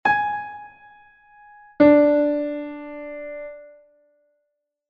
intervalocompuesto_2.mp3